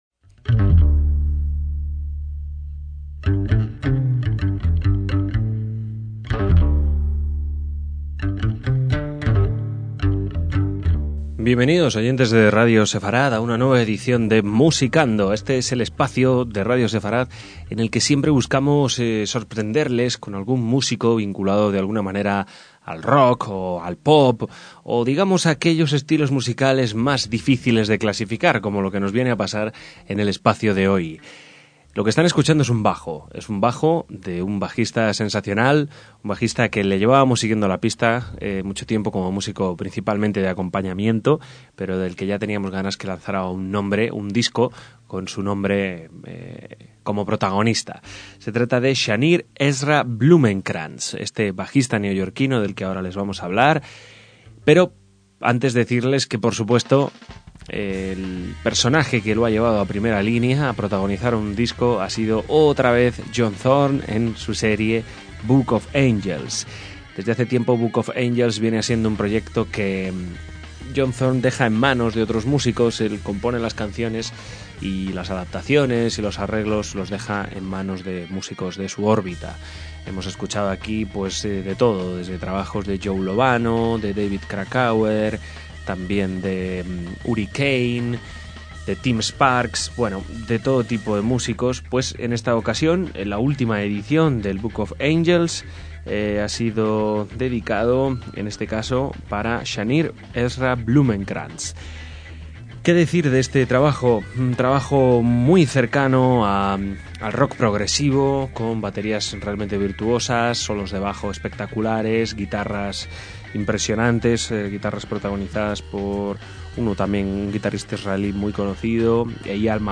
bajista estadounidense
laúd árabe
jazz de vanguardia